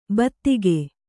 ♪ battige